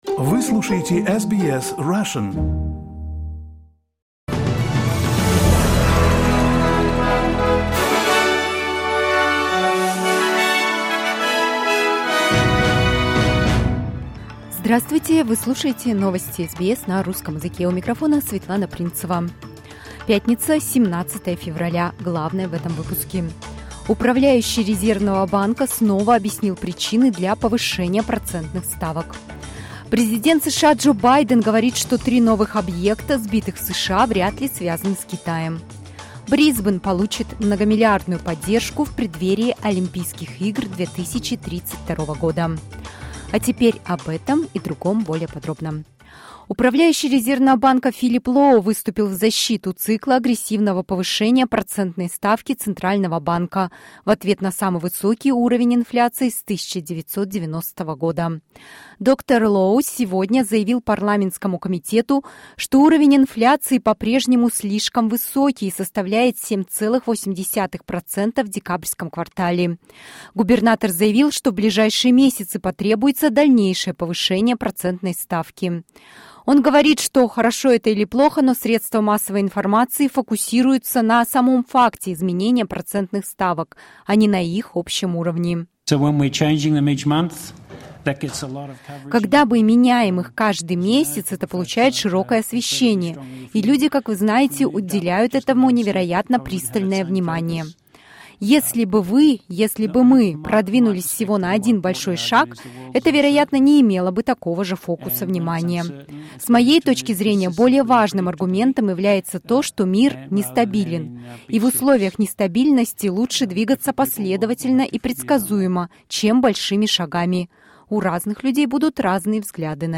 SBS news in Russian — 17.02.2023